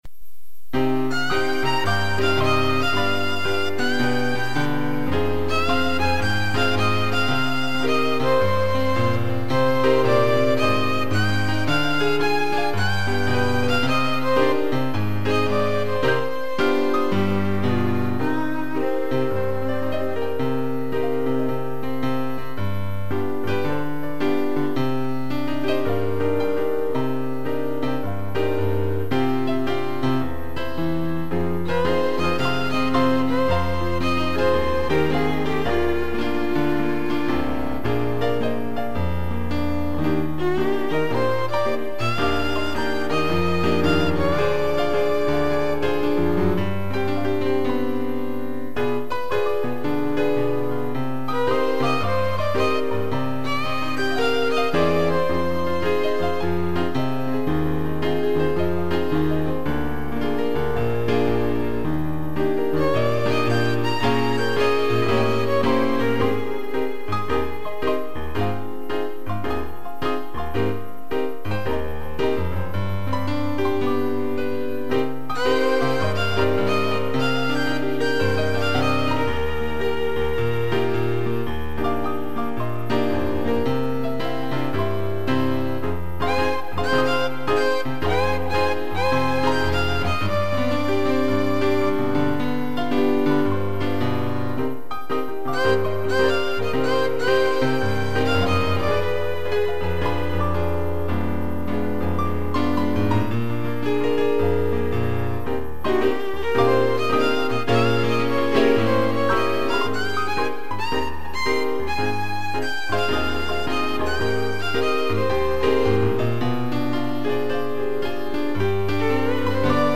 piano e violino
(instrumental)